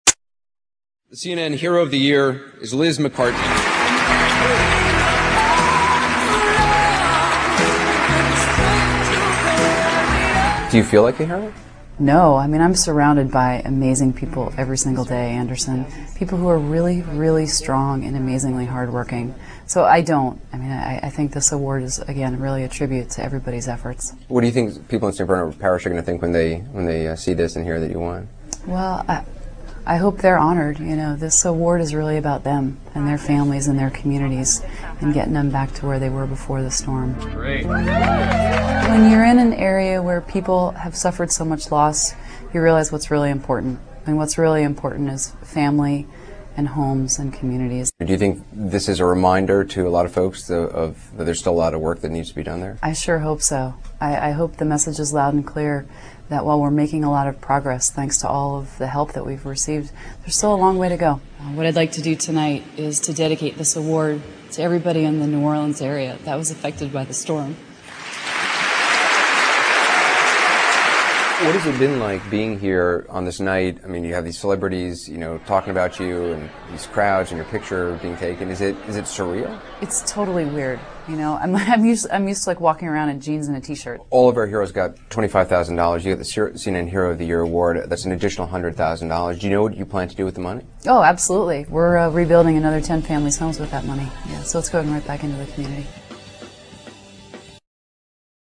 2008年度CNN人物访谈节目